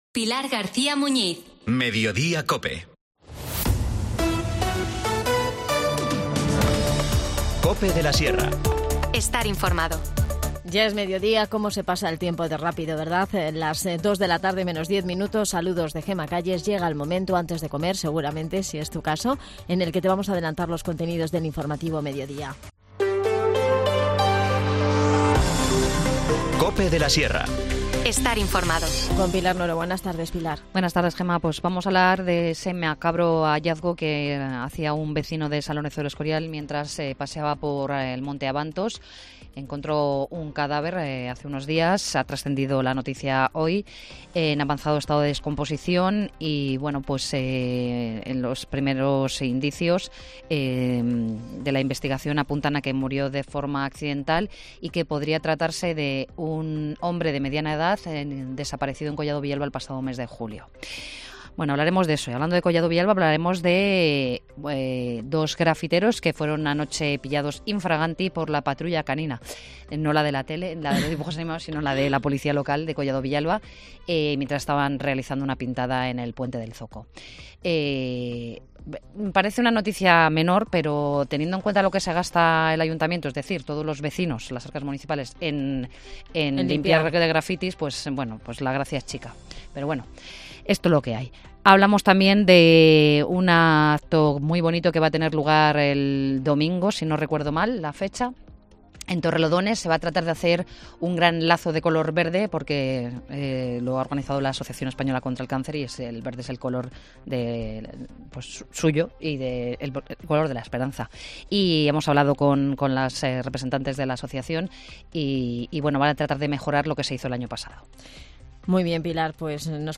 INFORMACIÓN LOCAL
Elena Álvarez, concejal de Juventud, nos cuenta cómo jóvenes y mayores pueden participar en esta bonita iniciativa.